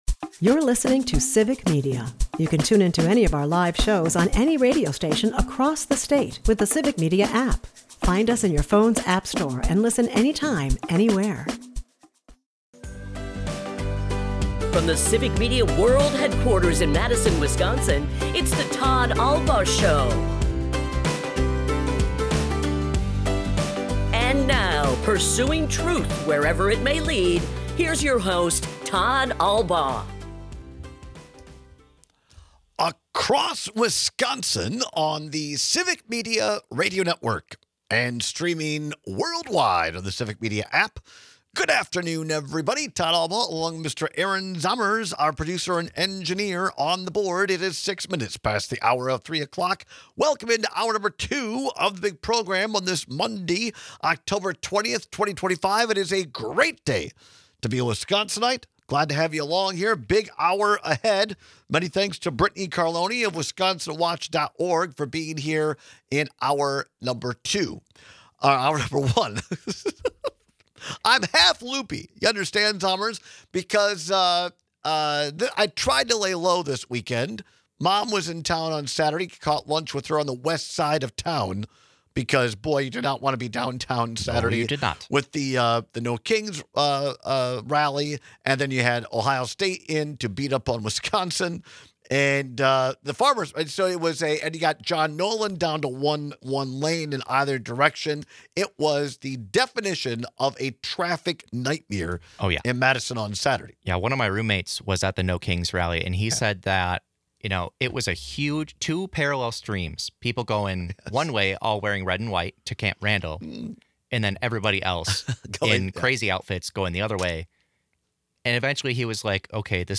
We take your calls and texts on whether an affront to the eyes or ears bothers you more.